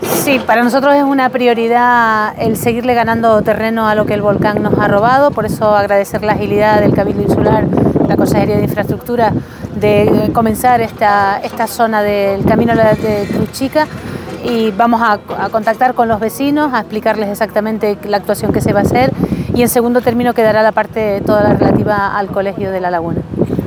Declaraciones Noelia García.mp3